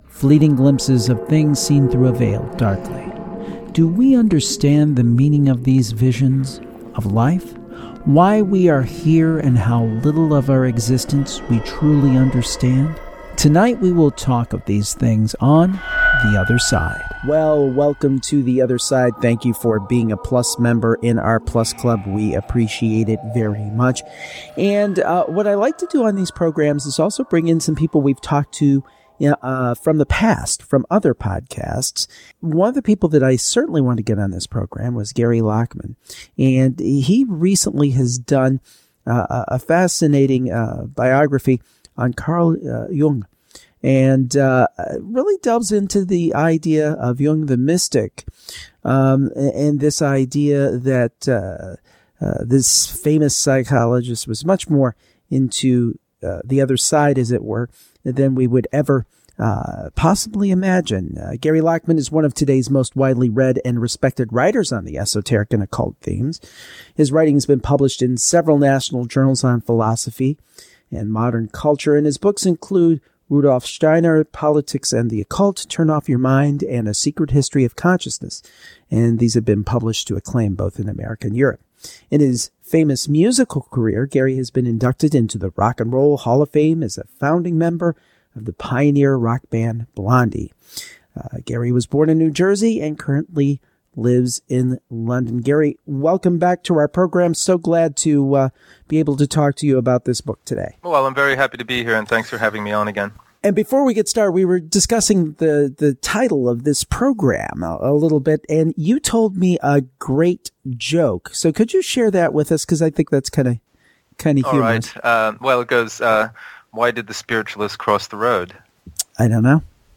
An interview about Jung the Mystics